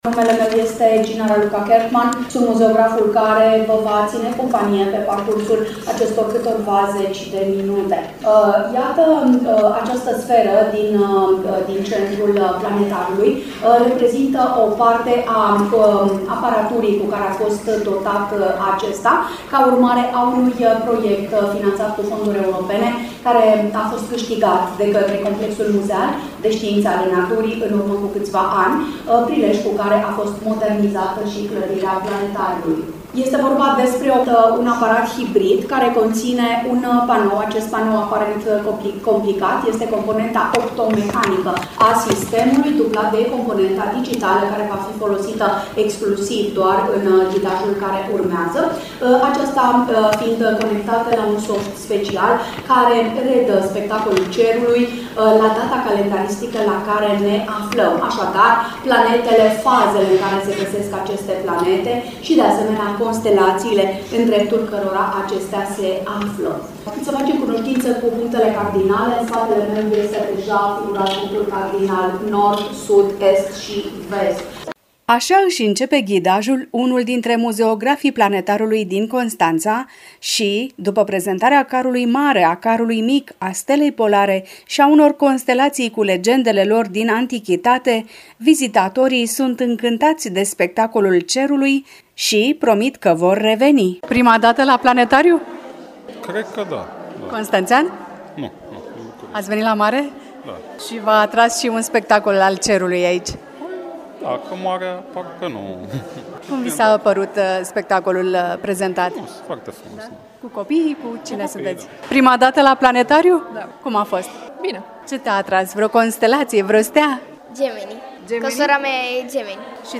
Detalii, în reportajul